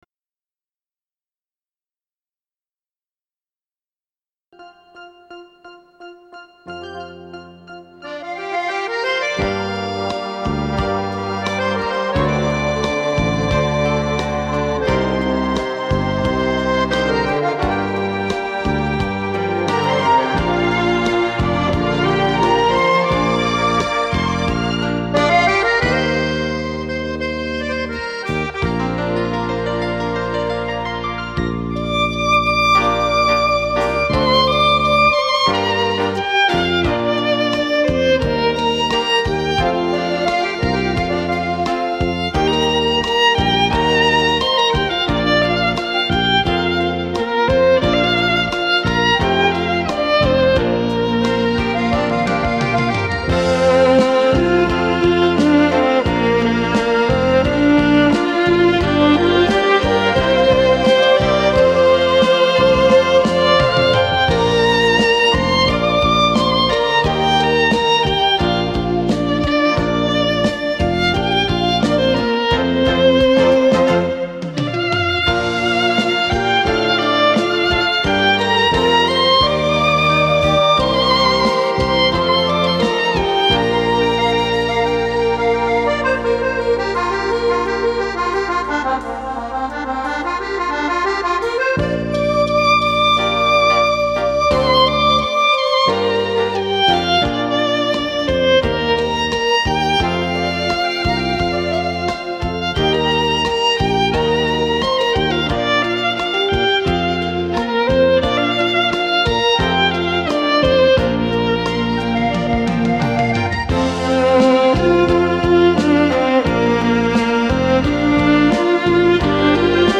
light]经典情歌